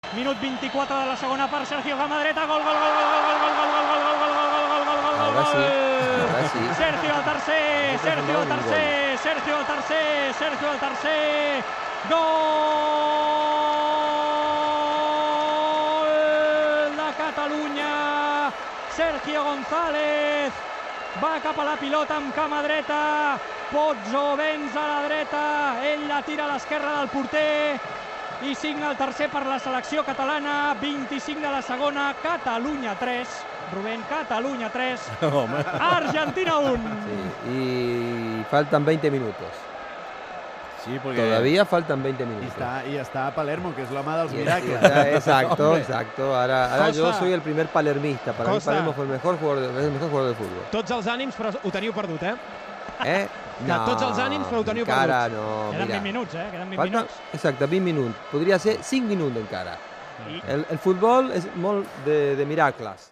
Narració dels gols del partit entre les seleccions de Catalunya i Argentina
Esportiu